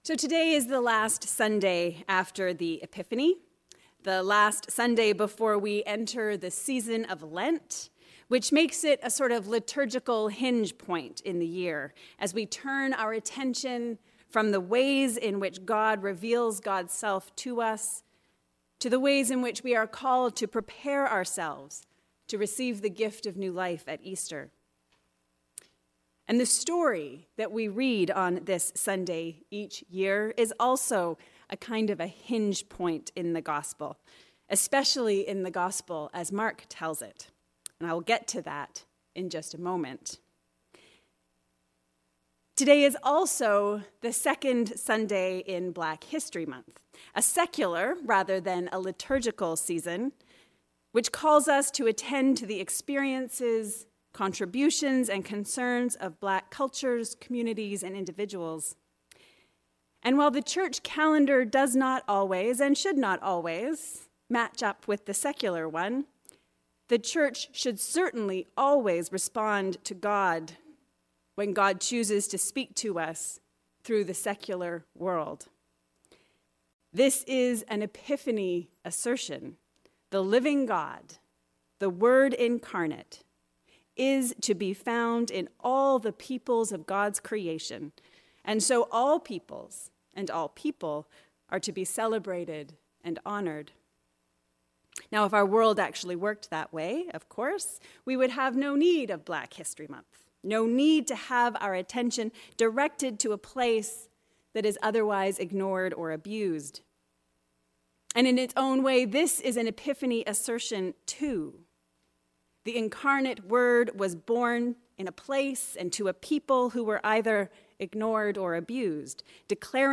A sermon on the Last Sunday of Epiphany which is also the second Sunday of Black History Month